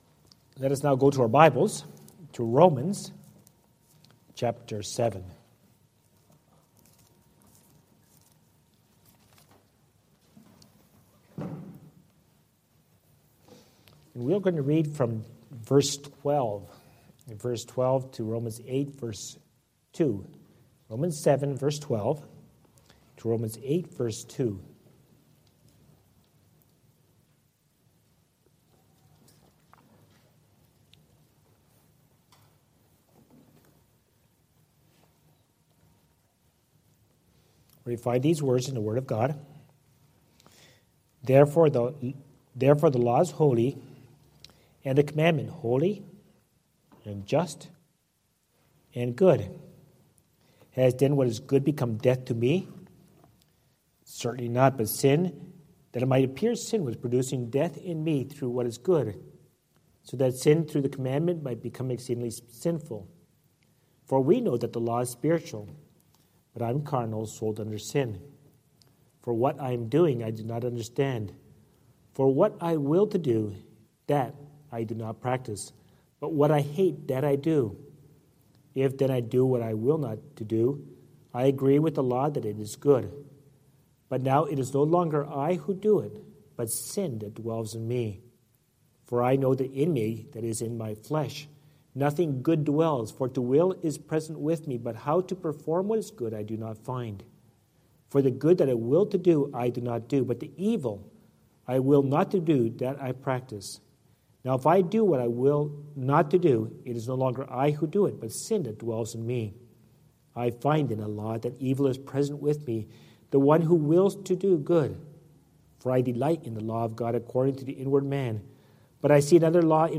The Tenth Commandment | SermonAudio Broadcaster is Live View the Live Stream Share this sermon Disabled by adblocker Copy URL Copied!